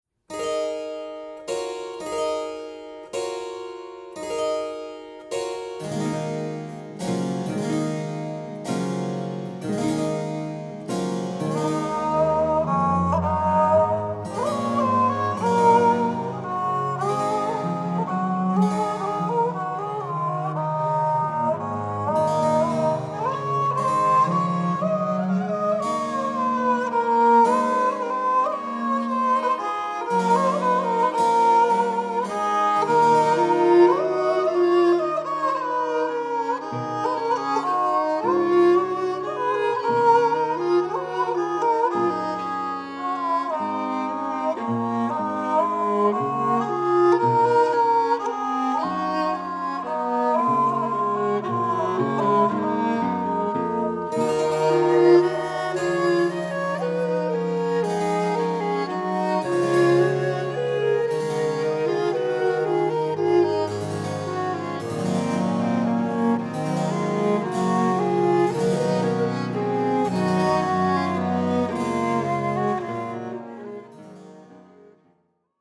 Recorded on November 25 & 26, 2000 in Santa Cruz, California
Genre: Early Music, Ottoman Classical.